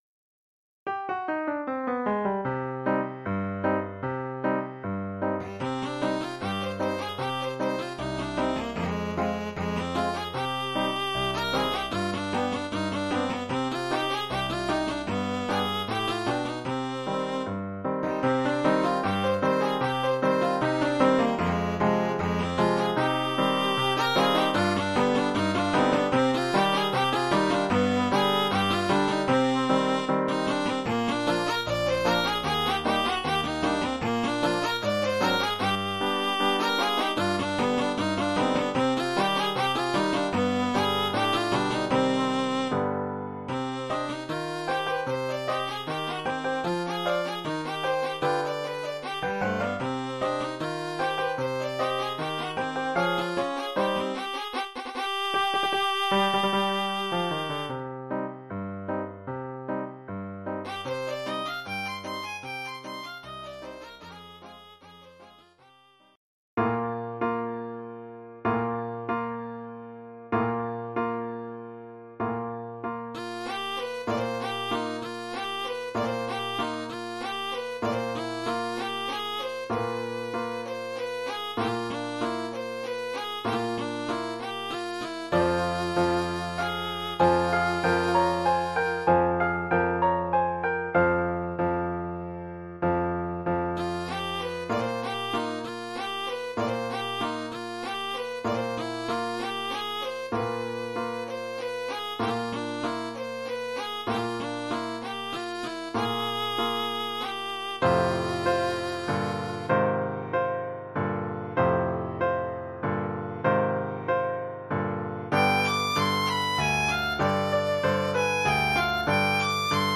Oeuvre pour violon et piano.